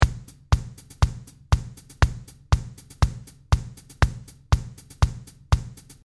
まずは簡単なドラムのキックとハイハットで単調なリズムを作ることに成功しました。